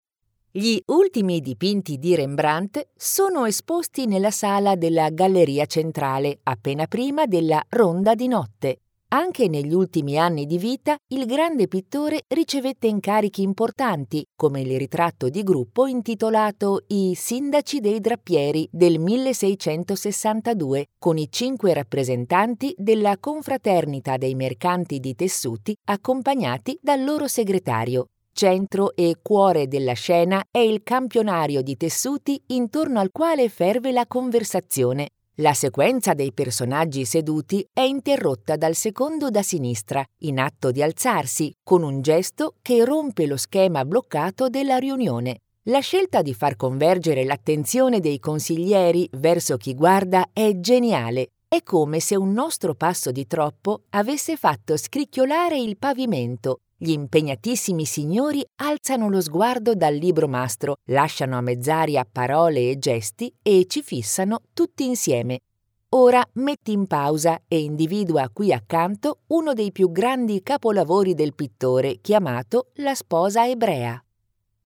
Italian female voice talent, Experienced, versatile, friendly, educated, assured.Warm and clear for narration, more young for commercial, professional and smooth for presentation
Sprechprobe: Sonstiges (Muttersprache):